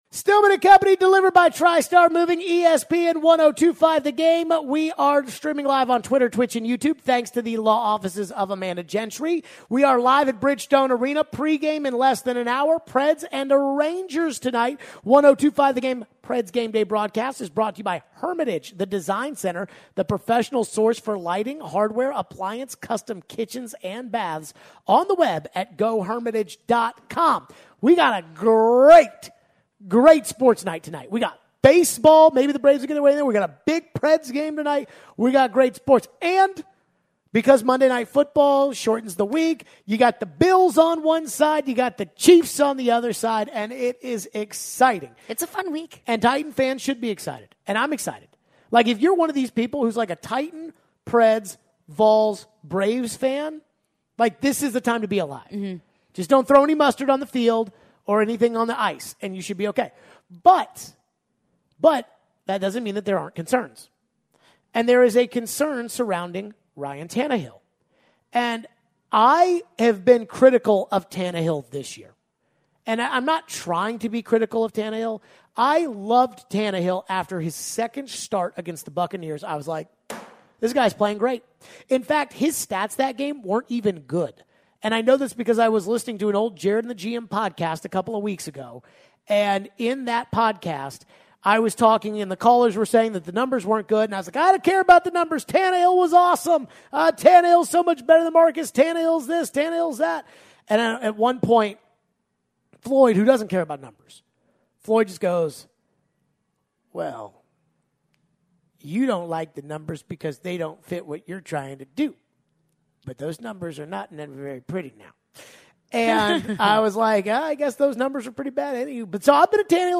We take your phones.